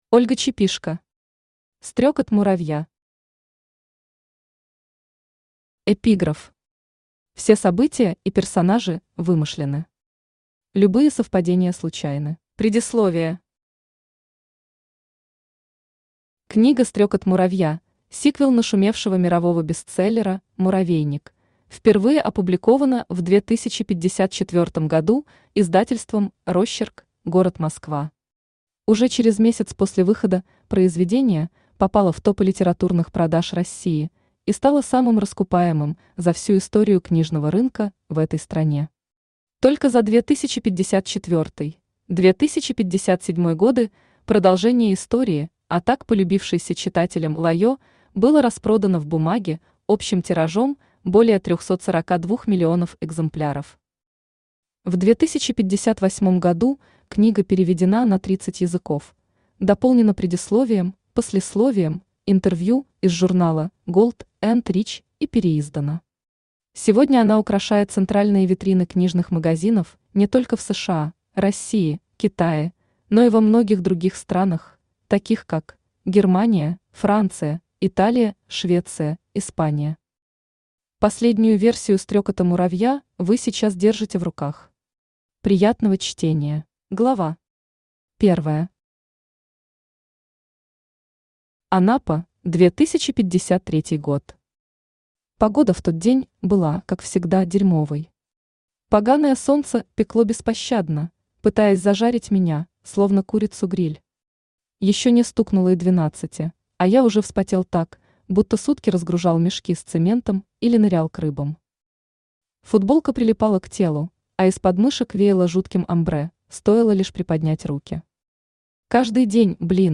Aудиокнига Стрекот муравья Автор Ольга Чепишко Читает аудиокнигу Авточтец ЛитРес. Прослушать и бесплатно скачать фрагмент аудиокниги